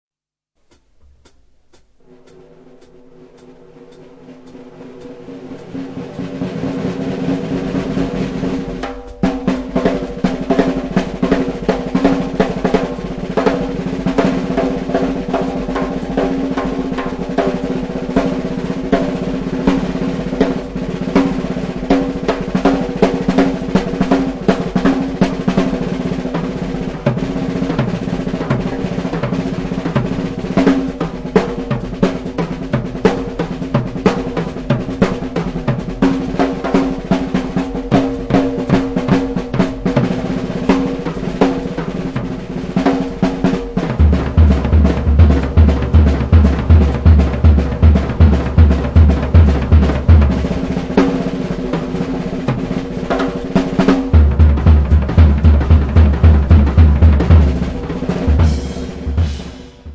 Live in Poggio alla Croce (Fi), 23 luglio 2005
batteria